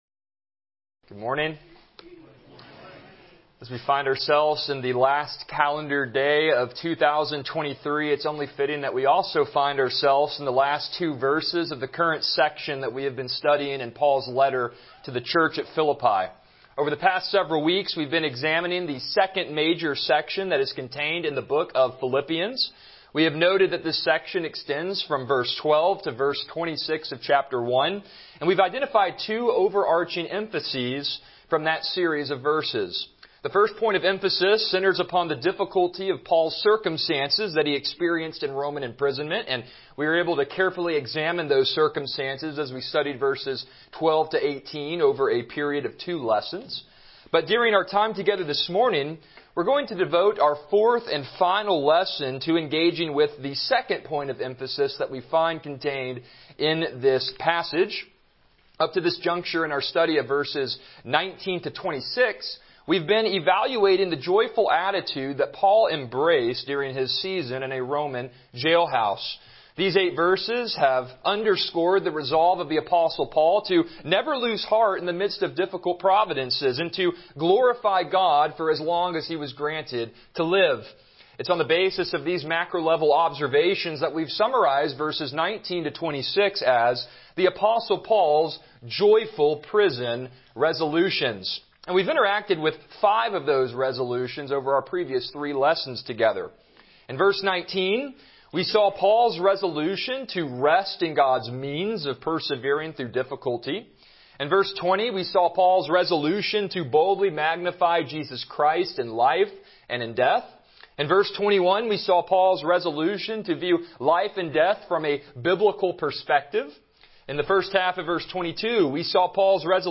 Passage: Philippians 1:25-26 Service Type: Morning Worship